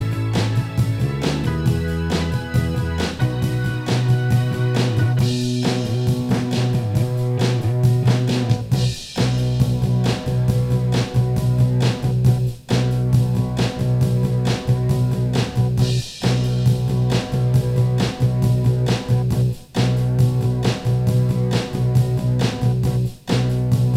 Minus Guitars Rock 3:52 Buy £1.50